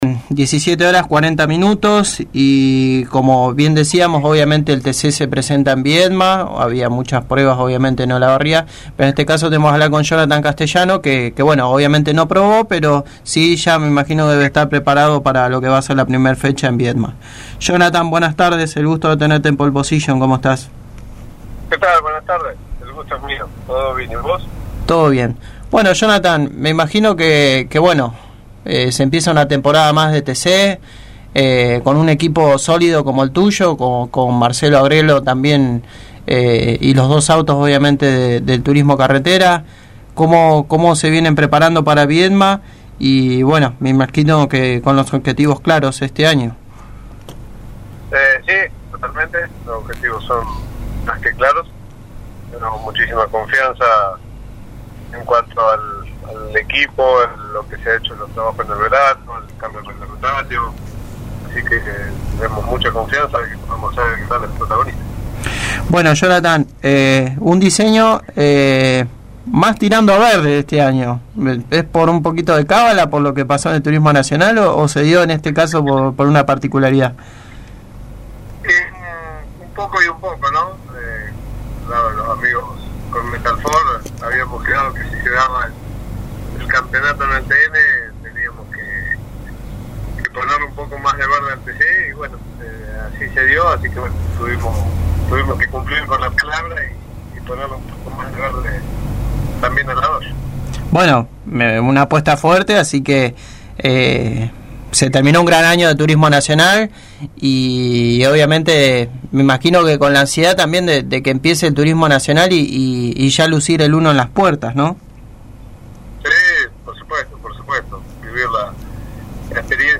El piloto de Loberia pasó por los micrófonos de Pole Position y nos contó como se prepara para el inicio de temporada del Turismo Carretera, este fin de semana en Viedma. Habló del cambio reglamentario para este año y la gran cantidad de usuarios que se sumaron a la marca para esta temporada. Destacó que no pudieron probar, ya que llegaron con lo justo con los trabajos en los autos, por las demoras en mandar los elementos para instalar la tuerca central.